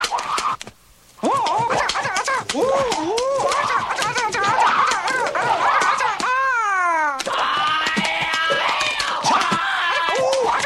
Screaming Free sound effects and audio clips
• yelling sound effect 7.ogg
[yelling-sound-effect]-1_(8)_abz.wav